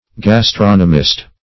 Gastronomist \Gas*tron"o*mist\, n.